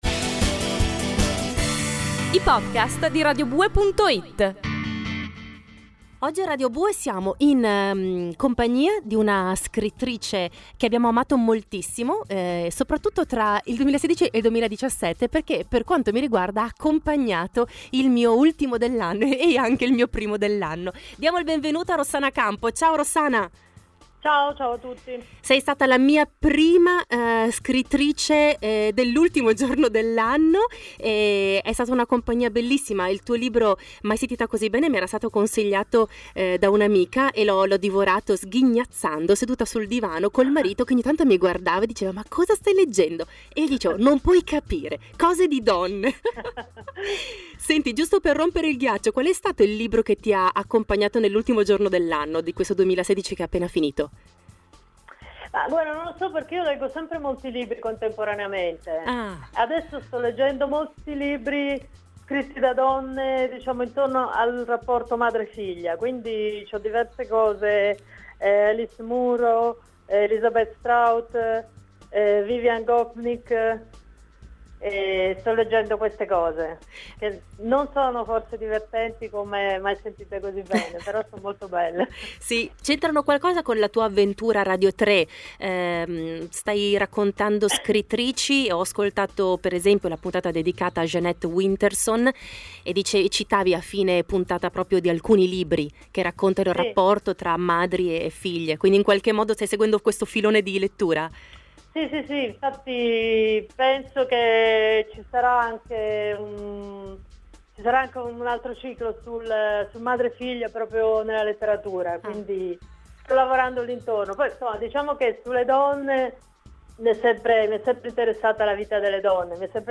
“Difficoltà per le ragazze”, intervista a Rossana Campo